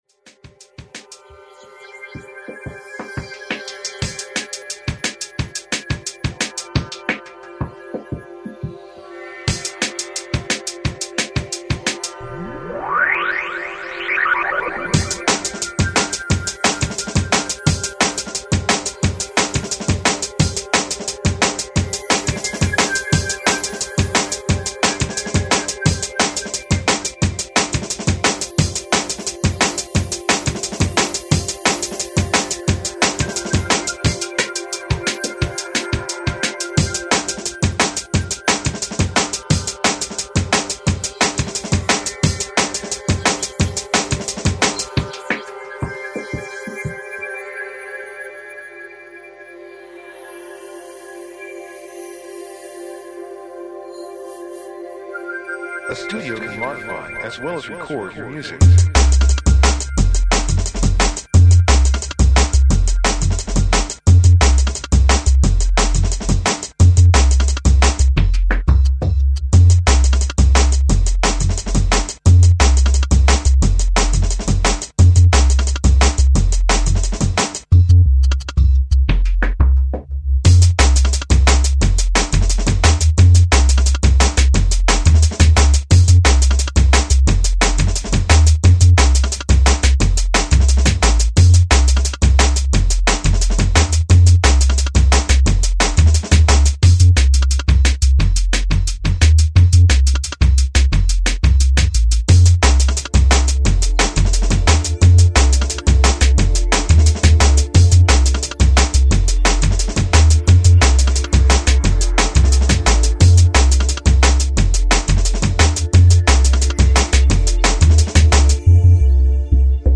futuristic dark sounds
Techstep